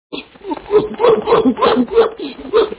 gorille hoot.mp3